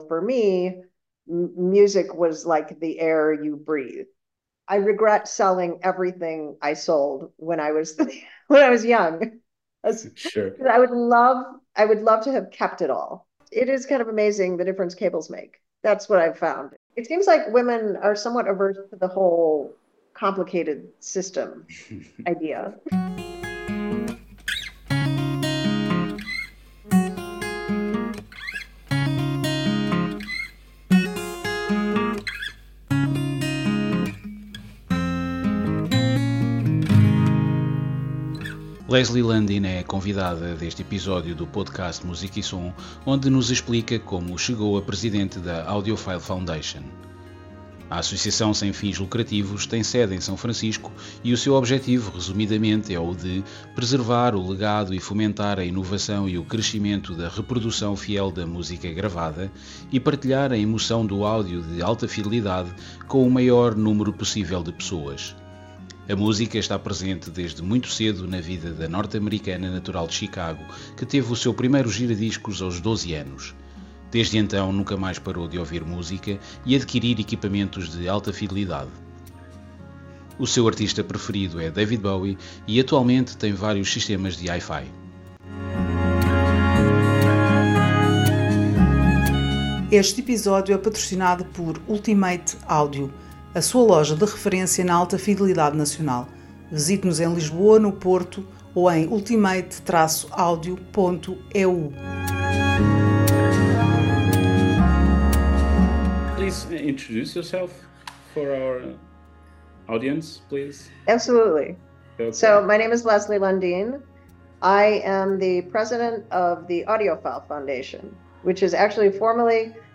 Entrevista em língua Inglesa.